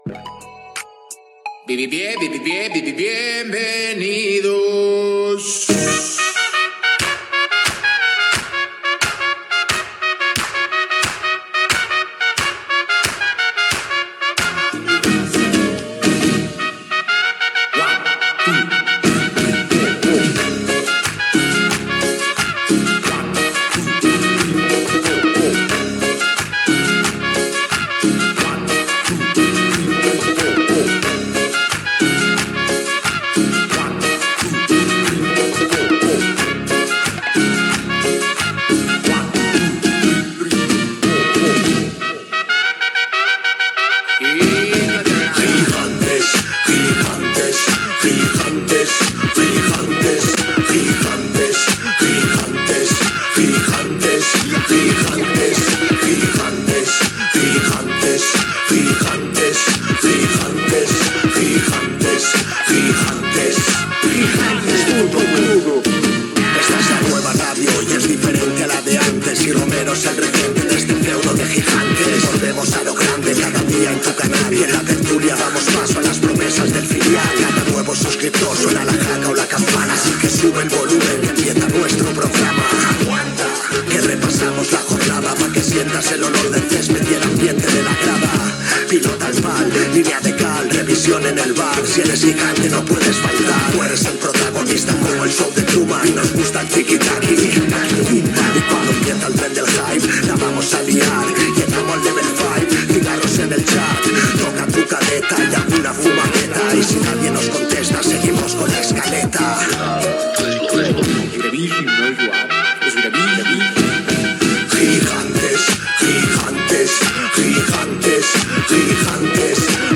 Música inicial del canal de Twitch